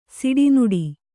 ♪ siḍi nuḍi